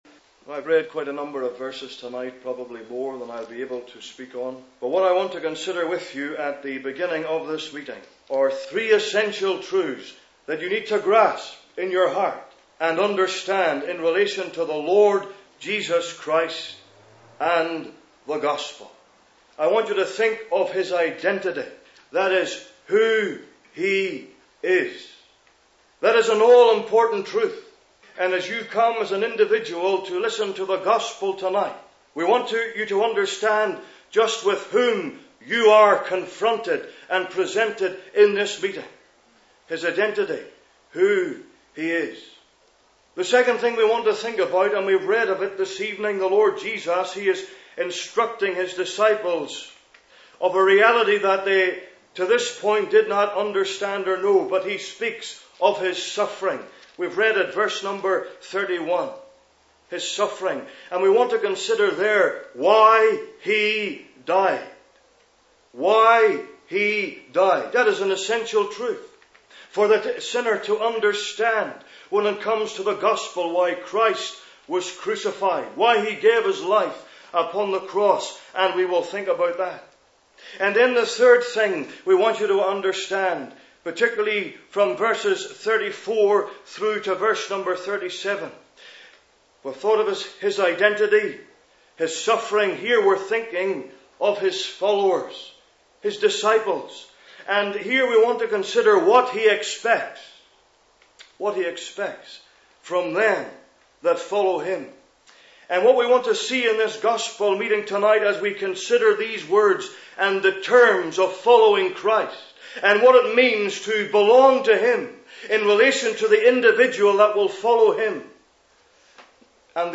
(Gospel messages preached Monday 25th August 2008)